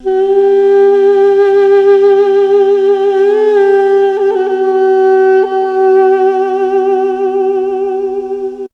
ZG FLUTE 2.wav